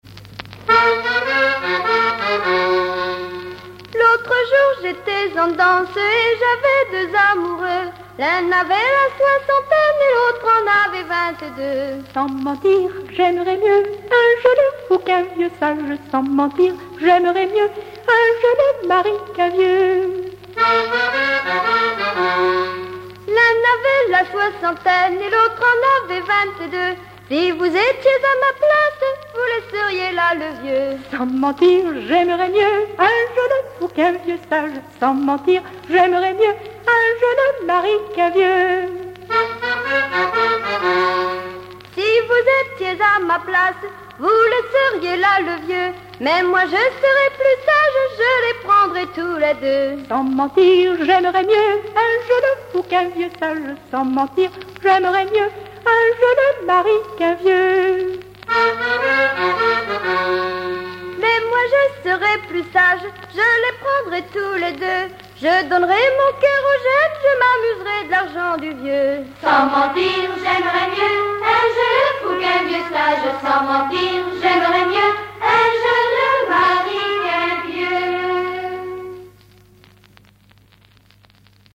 Chansons en dansant
Genre laisse
Pièce musicale inédite